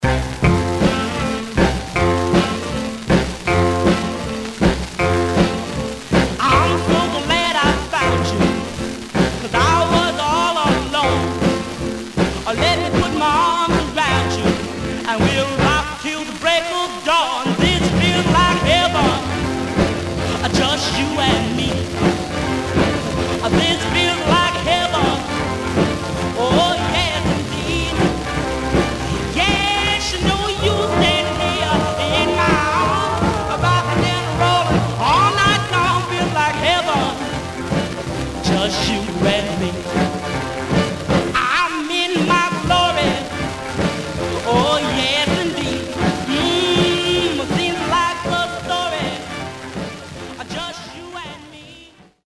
Surface noise/wear
Mono
Rythm and Blues